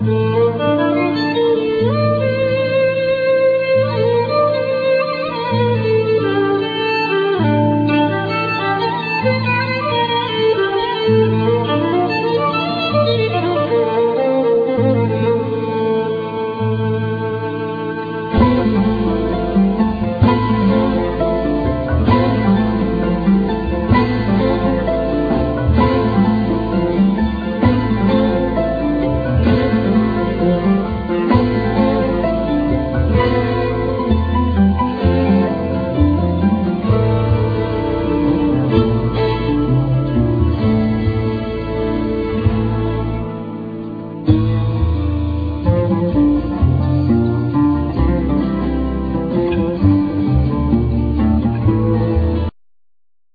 Violin,12 hornes acoustic guitar
Oud
Santouri
Bass
Keyboards
Drums
Percussions
Lyra